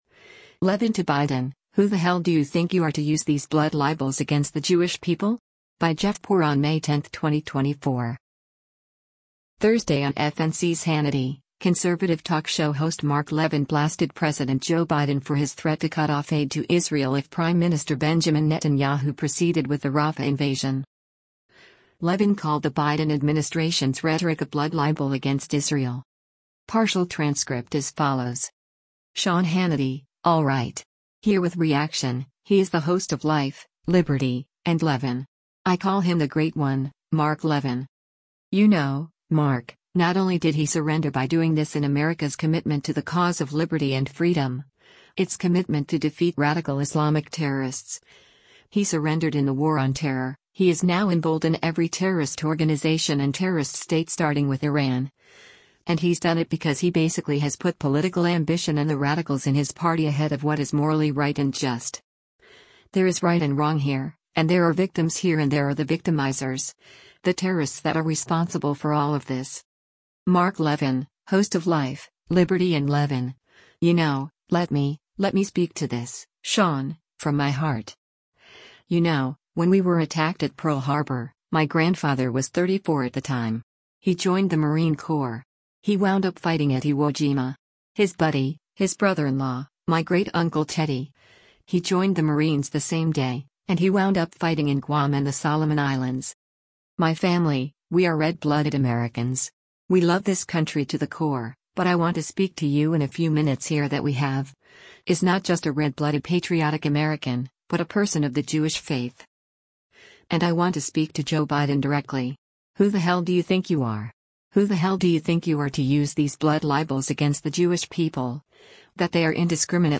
Thursday on FNC’s “Hannity,” conservative talk show host Mark Levin blasted President Joe Biden for his threat to cut off aid to Israel if Prime Minister Benjamin Netanyahu proceeded with the Rafah invasion.